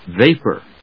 音節va・pour 発音記号・読み方
/véɪpɚ(米国英語), véɪpə(英国英語)/